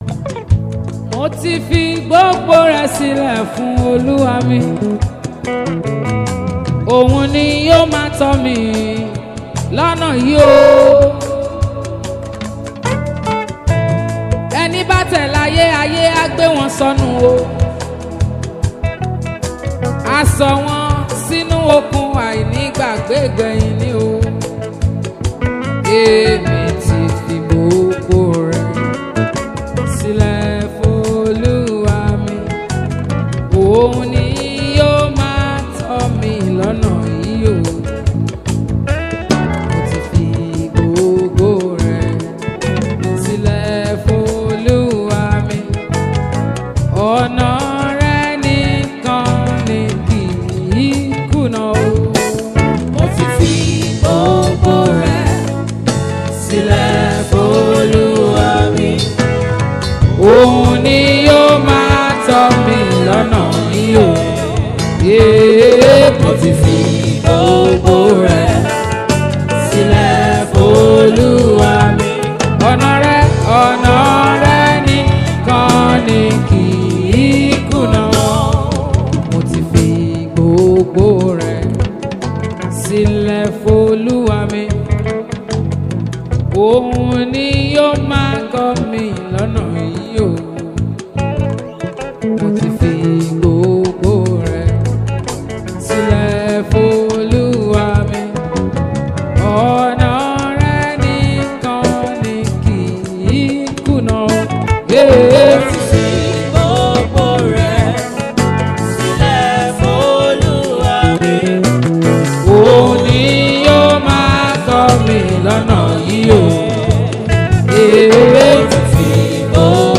Yoruba Gospel Music
a powerful worship song to uplift and inspire you.